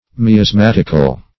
Search Result for " miasmatical" : The Collaborative International Dictionary of English v.0.48: Miasmatic \Mi`as*mat"ic\, Miasmatical \Mi`as*mat"ic*al\, a. [Cf. F. miasmatique.]